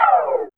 1805R SYNSLD.wav